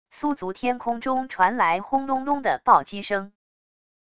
苏族天空中传来轰隆隆的暴击声wav音频